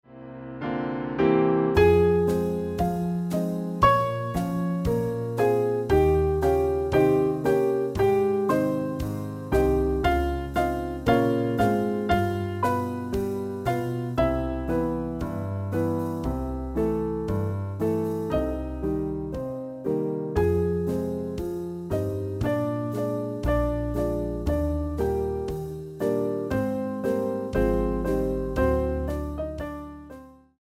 MIDI arrangement for Piano, Bass and Drums
Piano Channel 1
Bass Channel 5
Drums Channel 10